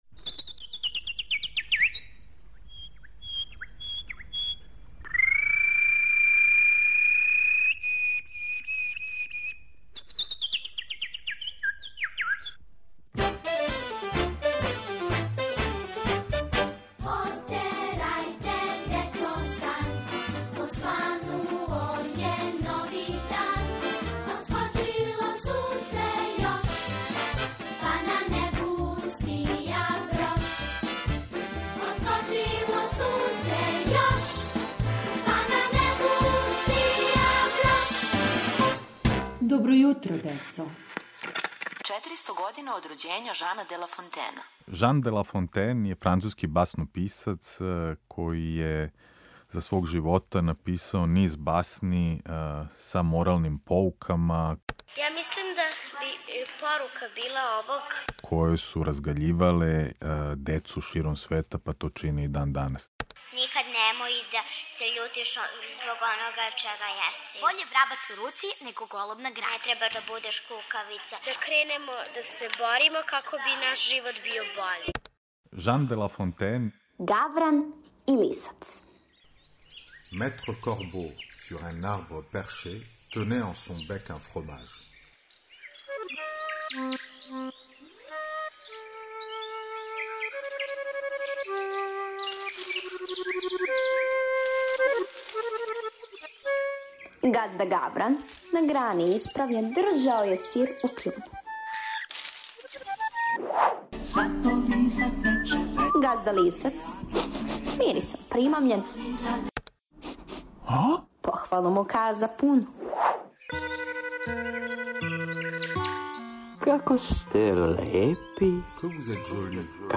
Серијал је обогаћен оригиналном музиком